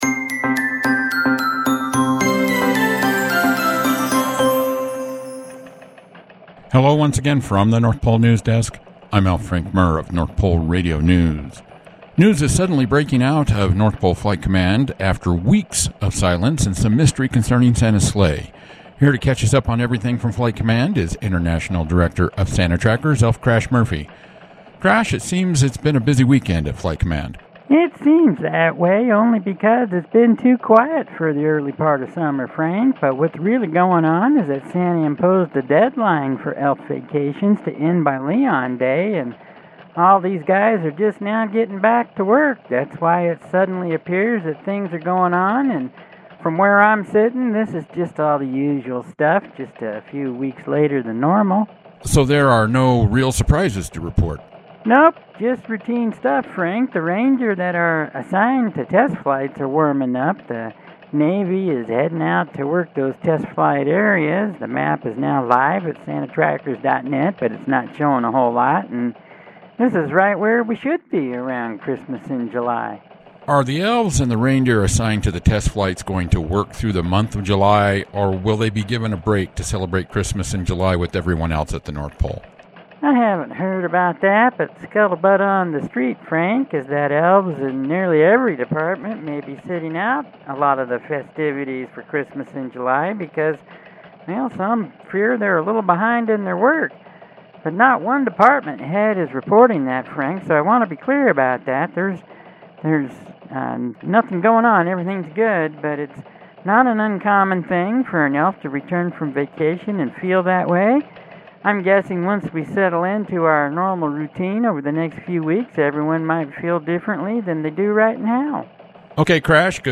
For an up-to-date look at all that’s going on with Operation Merry Christmas please listen to the North Pole Radio News report above.